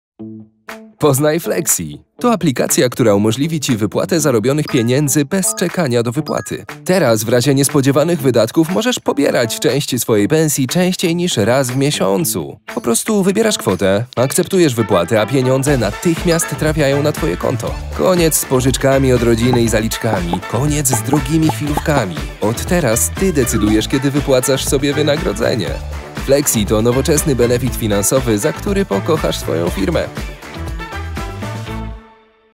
Mężczyzna 20-30 lat
Ciepły, spokojny tembr, który idealnie nadaje się do przeczytania filmu, serialu i poprowadzenia narracji.
Narracja lektorska
Spot reklamowy